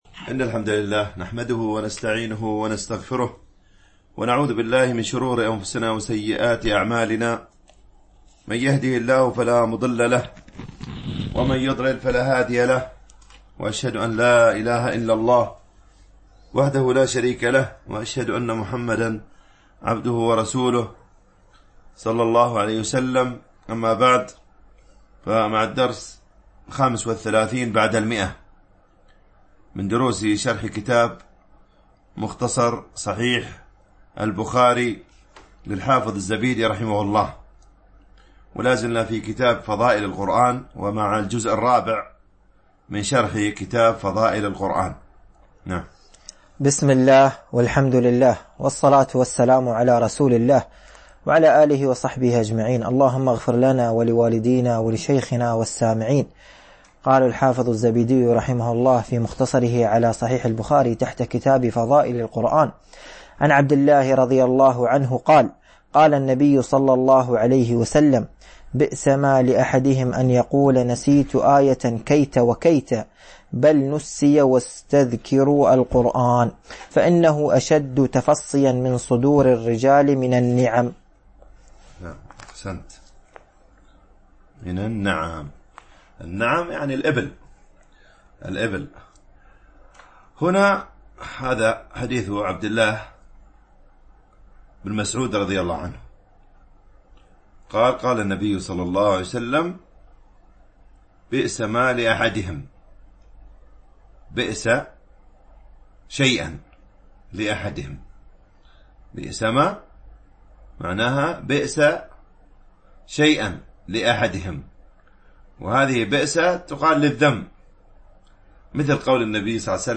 شرح مختصر صحيح البخاري ـ الدرس 135 ( كتاب فضائل القرآن ـ الجزء الرابع ـ الحديث 1820 – 1824 )